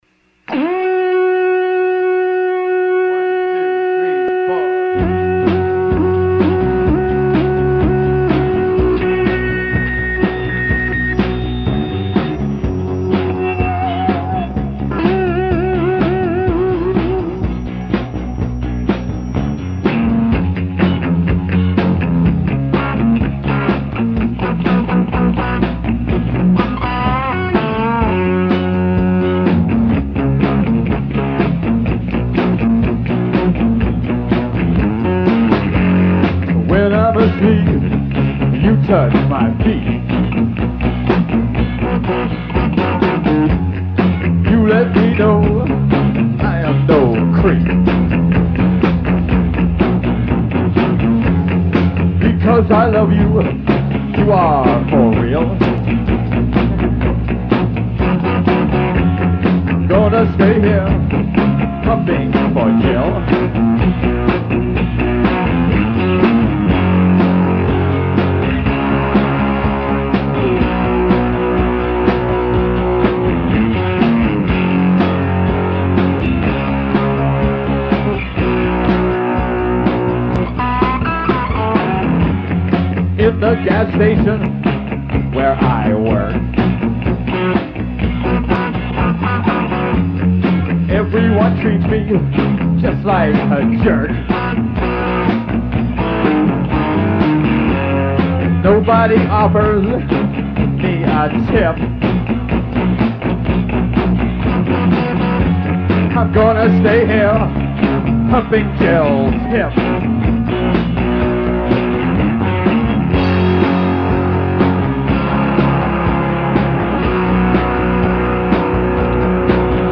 Rehearsals 5-12 + 17-1983